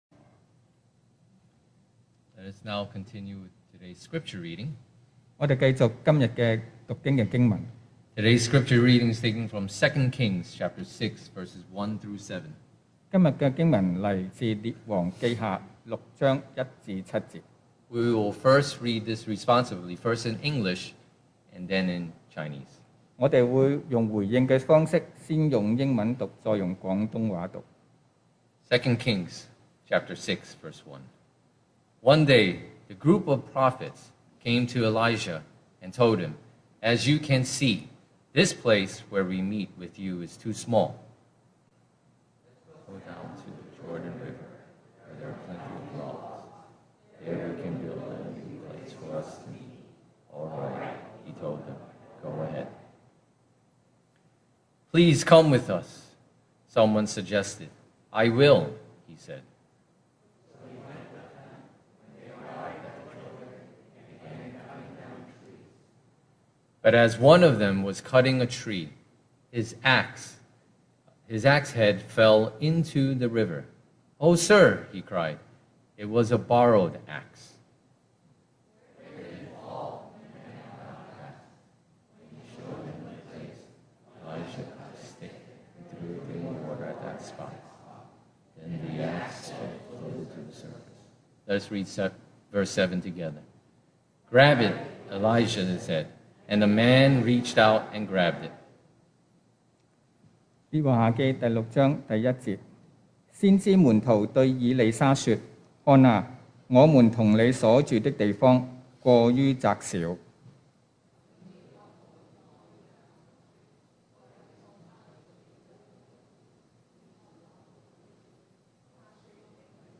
Passage: 2 Kings 6:1-7 Service Type: Sunday Morning